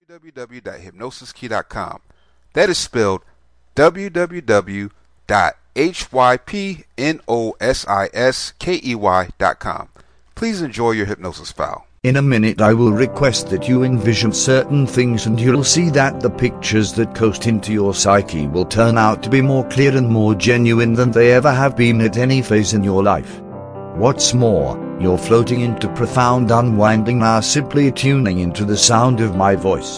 Nicer Nails Visualization Self Hypnosis Mp3